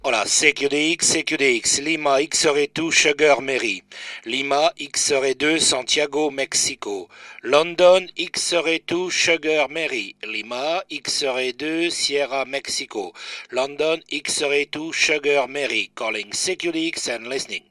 Type of microphone : Ceramic
Frequency response : 300Hz - 3.5kHz
My opinion, my best microphone with a cristal clear modulation tailored for ssb DX or the one who wants a powerfull AM modulation , silent pedal switch, full metal jacket.
Listen to the TURNER +3B sound (potentiometer at mid range, 50mV).